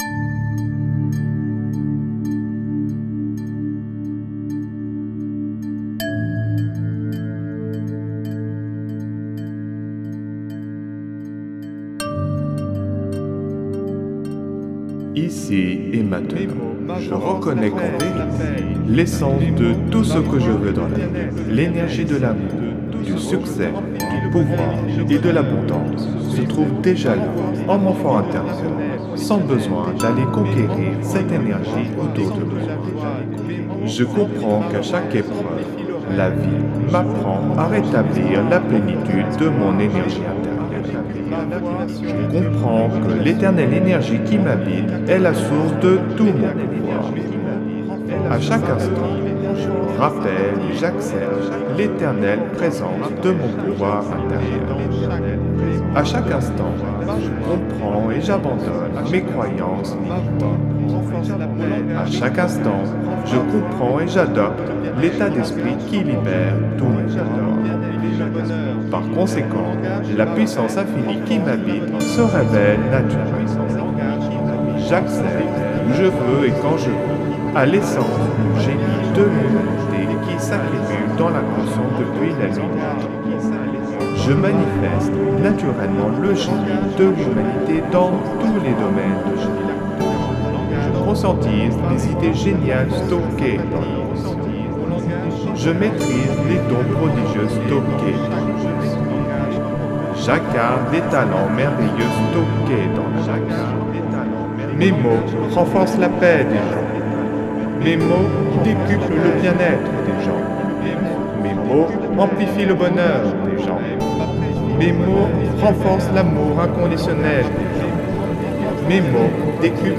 (Version ÉCHO-GUIDÉE)
LA QUINTESSENCE « tout-en-un » du développement personnel concentrée en un seul produit : Méditation, autosuggestion, message subliminal, musicothérapie, fréquences sacrées, son isochrone, auto hypnose, introspection, programmation neurolinguistique, philosophie, spiritualité, musique subliminale et psychologie.
Alliage ingénieux de sons et fréquences curatives, très bénéfiques pour le cerveau.
Puissant effet 3D subliminal écho-guidé.